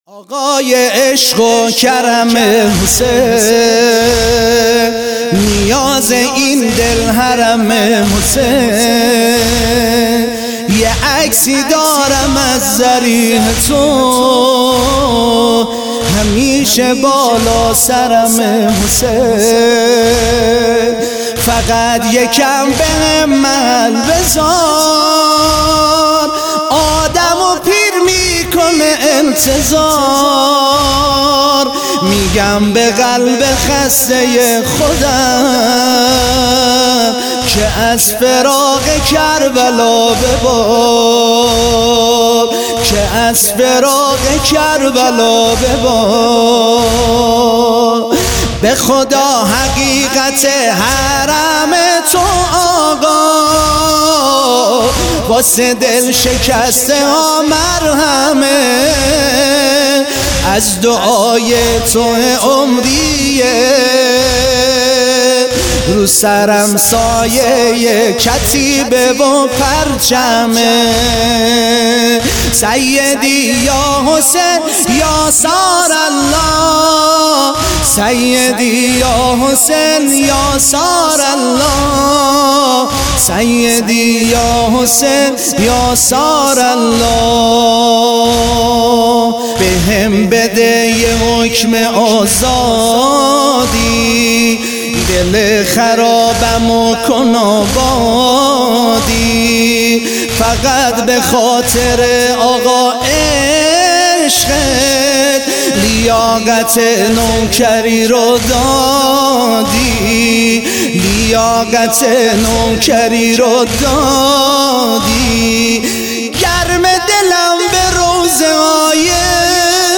محرم 99
نوحه شیرازی